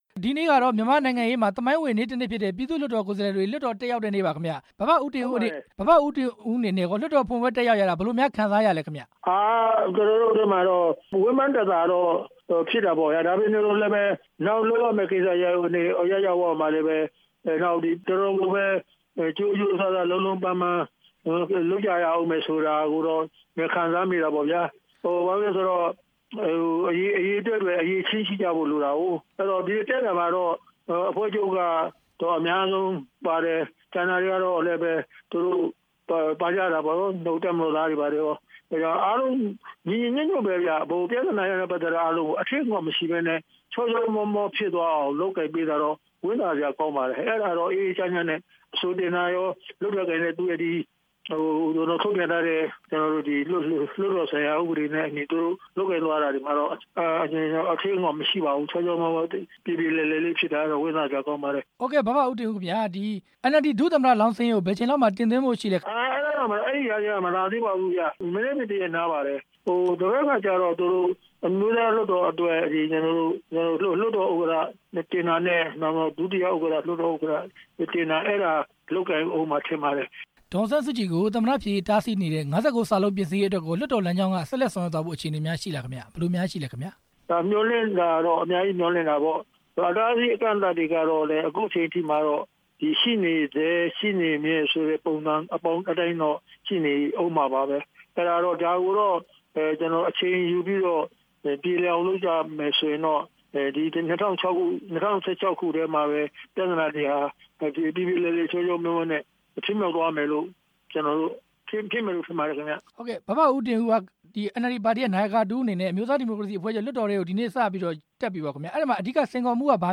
သူရဦးတင်ဦး ကို မေးမြန်းချက်